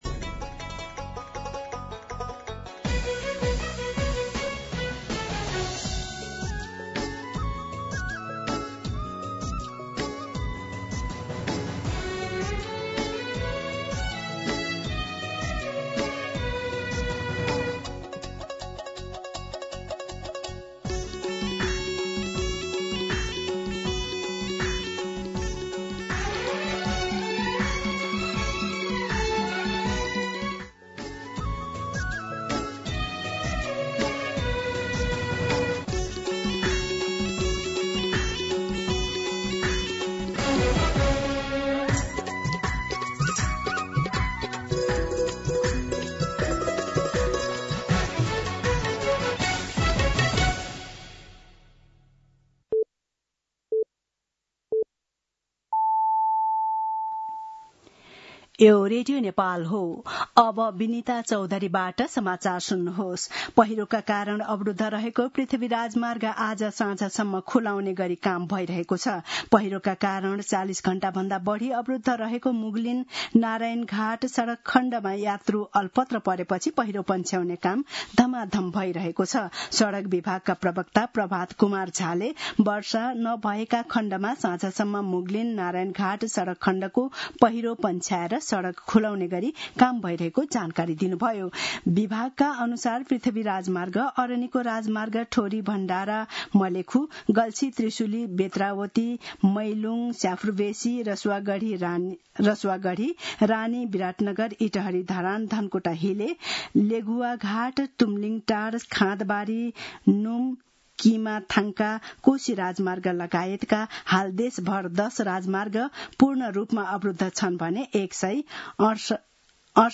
दिउँसो ४ बजेको नेपाली समाचार : ५ असोज , २०८२